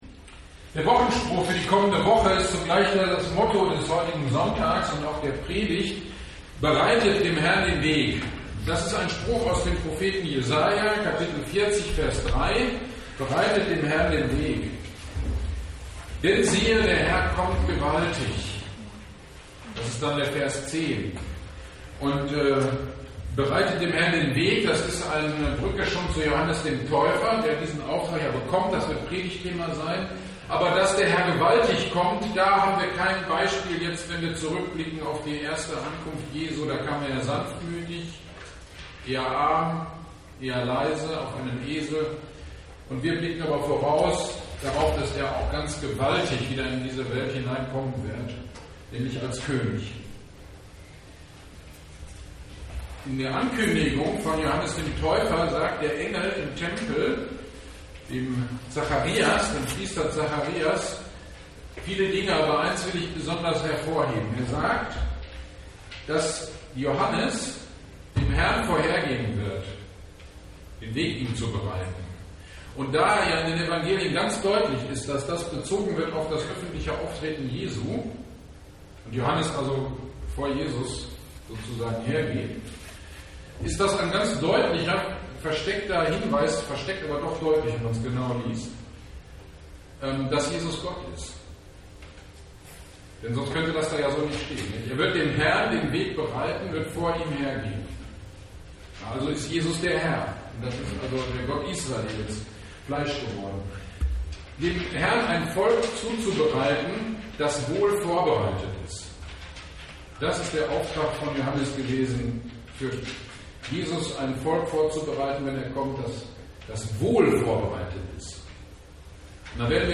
Advent 2021 - Predigt zu Matthäus 3.1-9 - Kirchgemeinde Pölzig